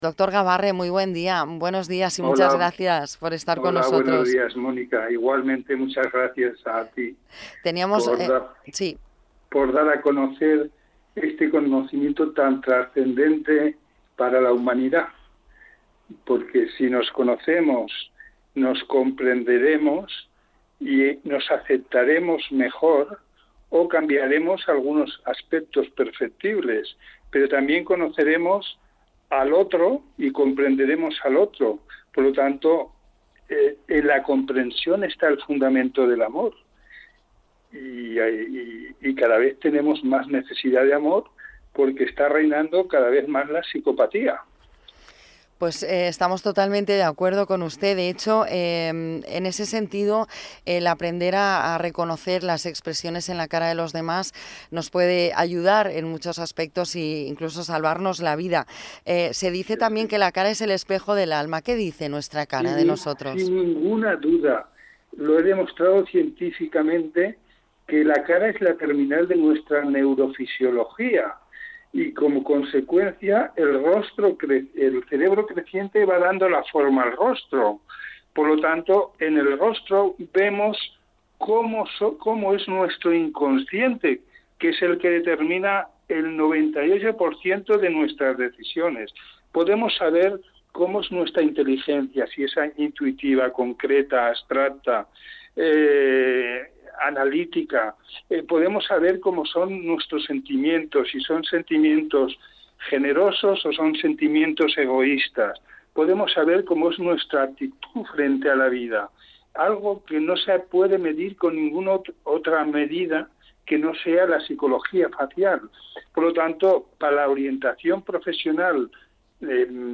Entrevista para Radio Illes Balears